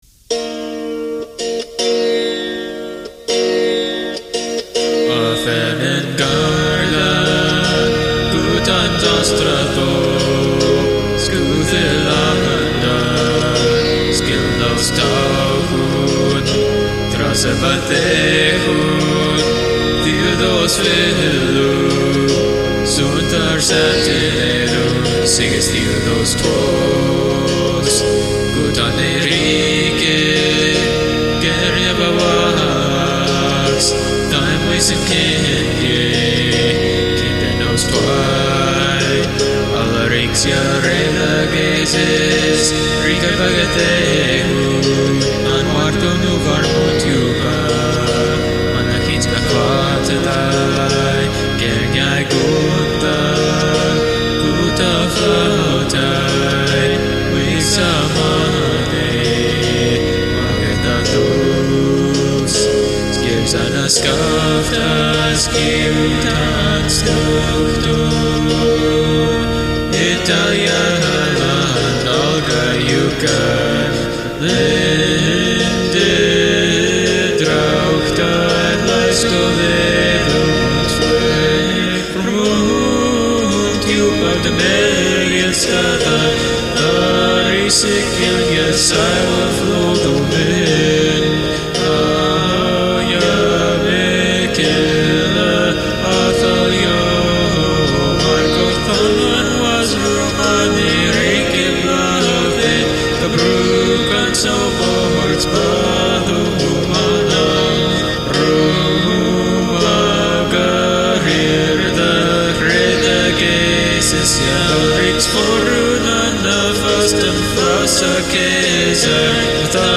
sounds like half neofolk, half nasheed geg